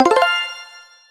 Level Completed (WAV).mp3